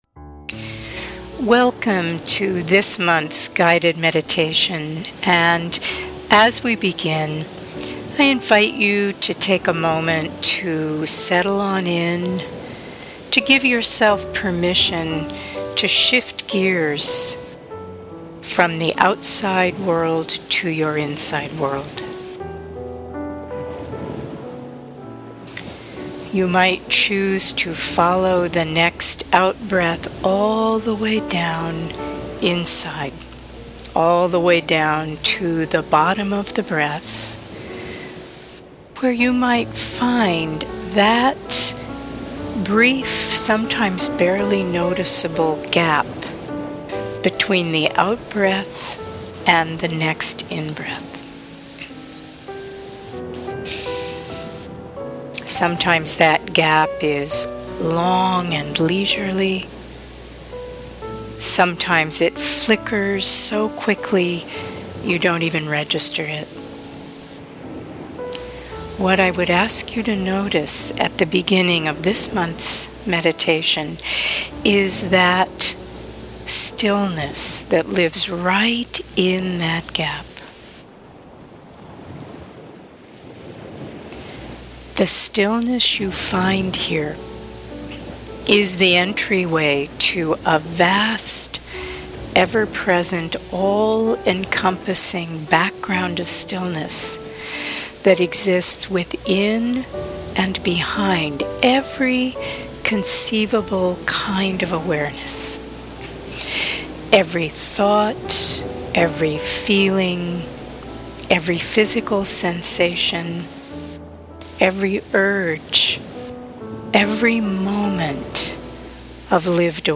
2023 November Meditation